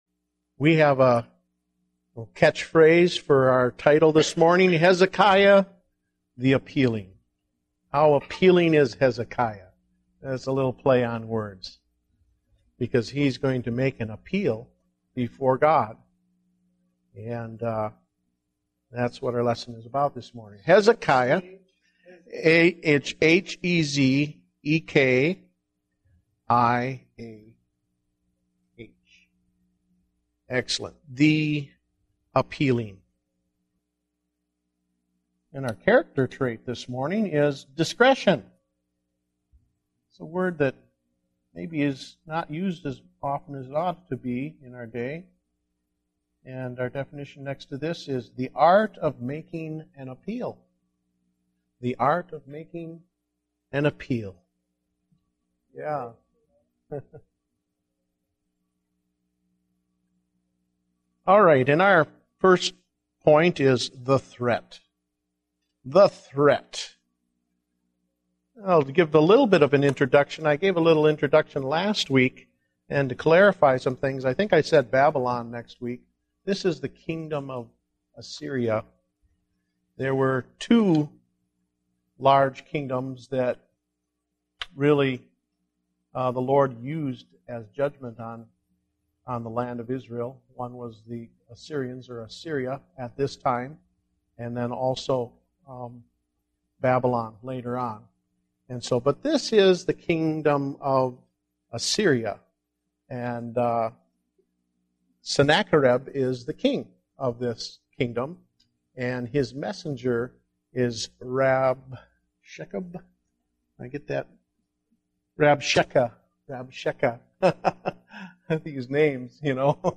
Date: February 14, 2010 (Adult Sunday School)